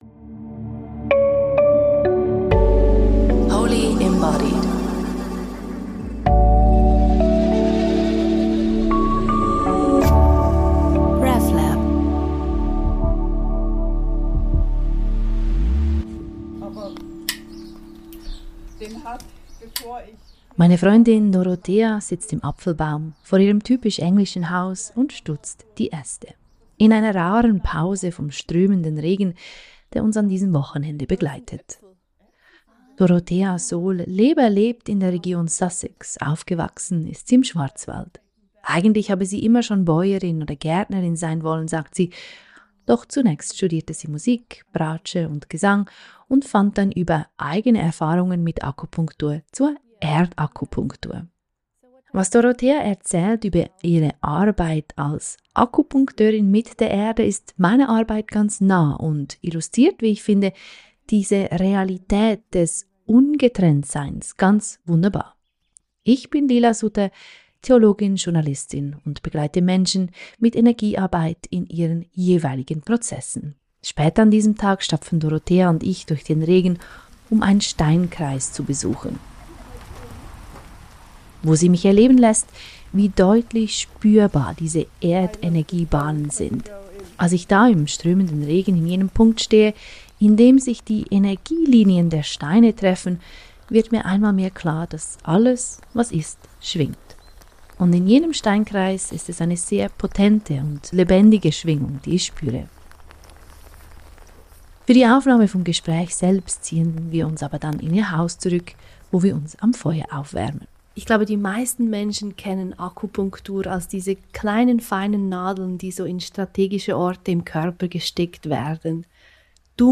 Ein Gespräch über Traurigkeit in Neubauten, Steinkreise und Elementarwesen.